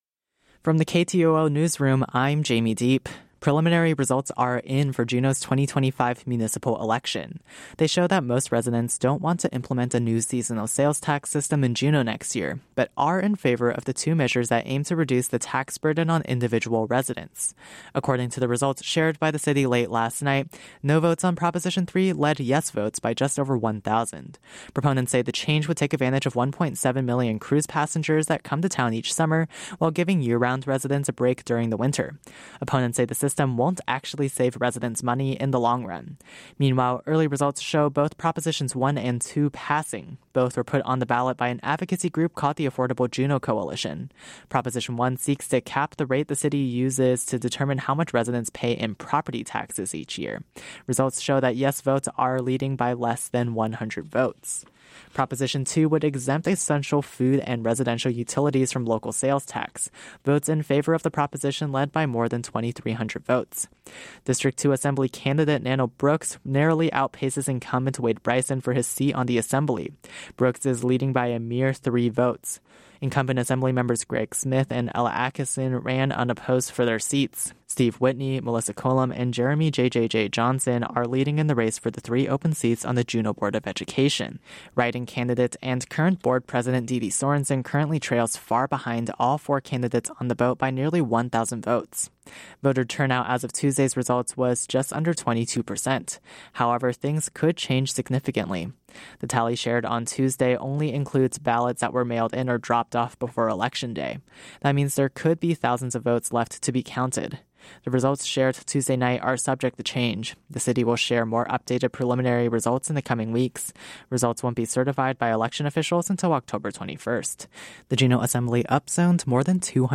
Newscast – Tuesday, Oct. 8, 2025 - Areyoupop